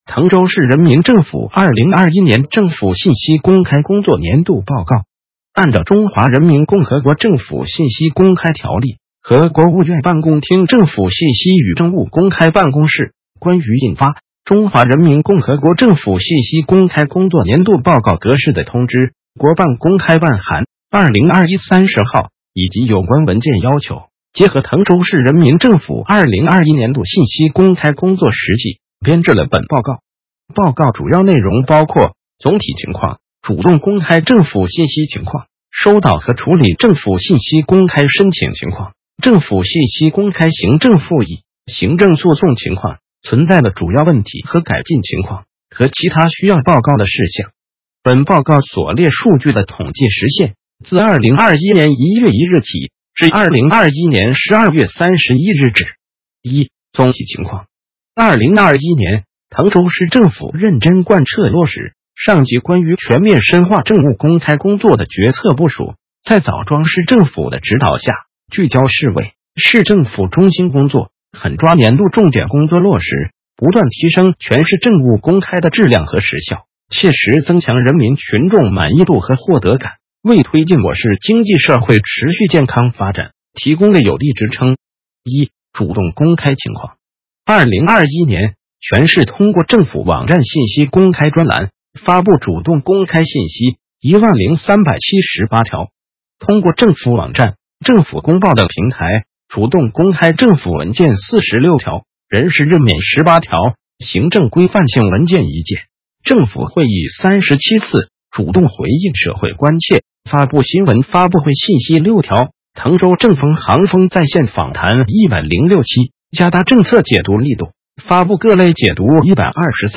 点击接收年报语音朗读 滕州市人民政府2021年政府信息公开工作年度报告 作者： 来自： 时间：2022-02-10 14:25:00 按照《中华人民共和国政府信息公开条例》和《国务院办公厅政府信息与政务公开办公室关于印发 < 中华人民共和国政府信息公开工作年度报告格式 > 的通知》（国办公开办函〔 202 1 〕 30 号）以及有关文件要求，结合滕州市人民政府 202 1 年度信息公开工作实际，编制了本报告。